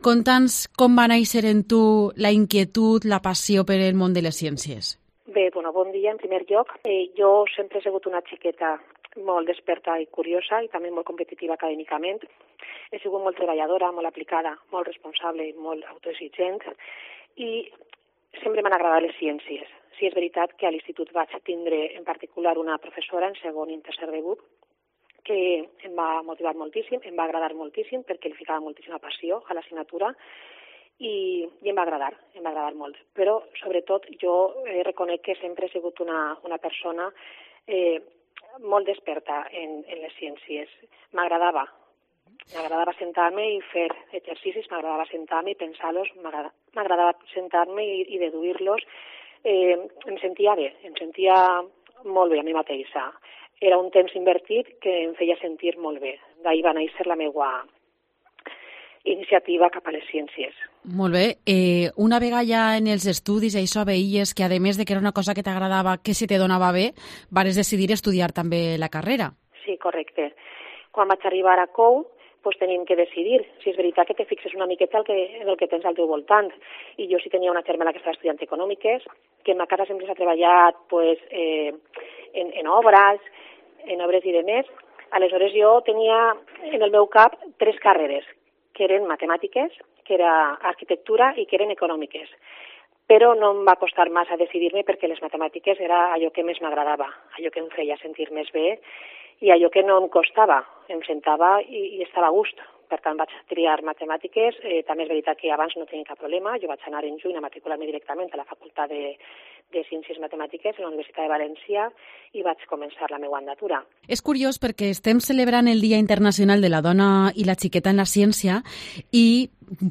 AUDIO: Especial entrevistas Día Internacional de la Mujer y la niña en la ciencia